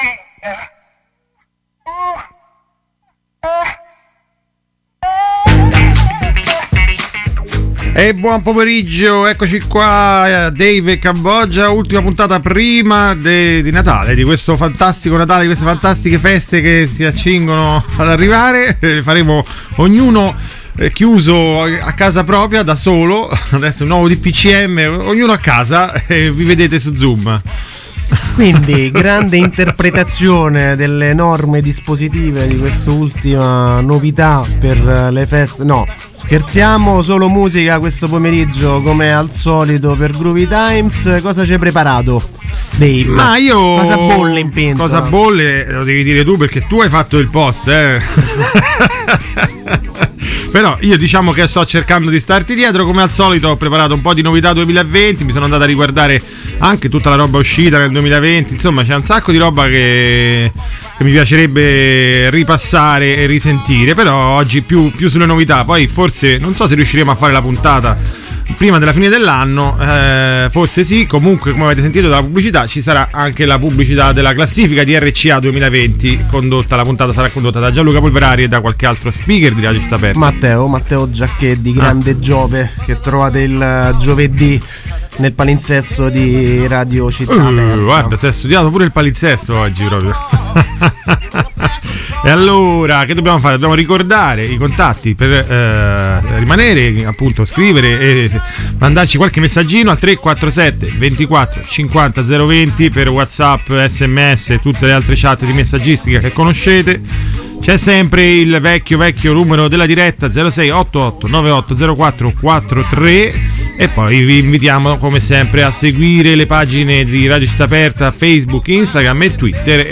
a tutto soul e funk